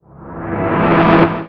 VEC3 FX Athmosphere 12.wav